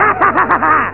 Amiga 8-bit Sampled Voice
laugh.mp3